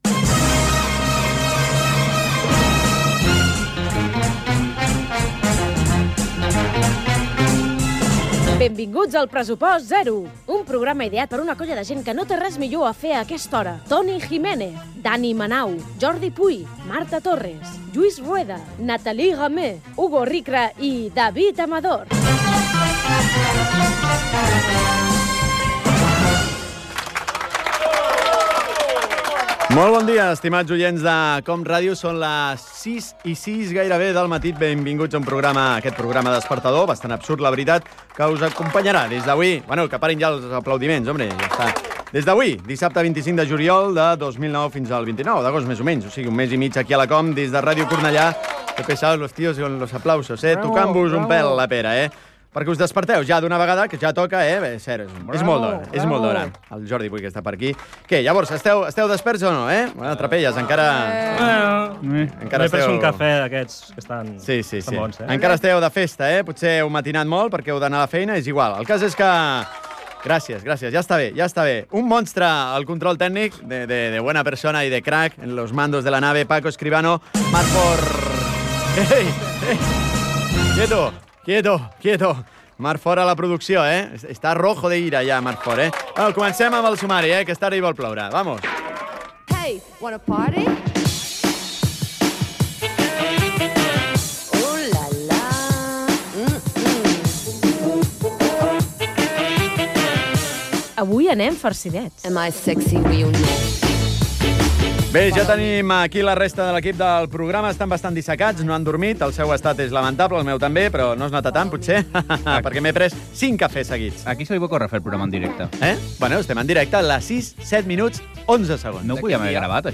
Inici del programa despertador de l'estiu. Crèdits del programa.
Entreteniment
FM